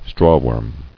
[straw·worm]